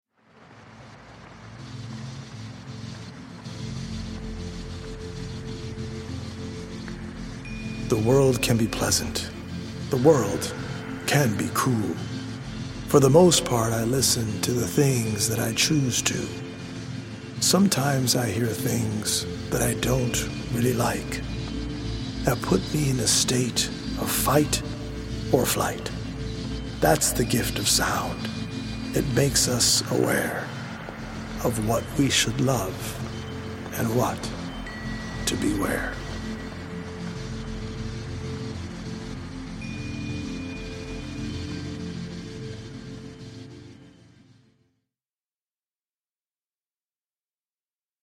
audio-visual poetic journey
healing Solfeggio frequency music
EDM producer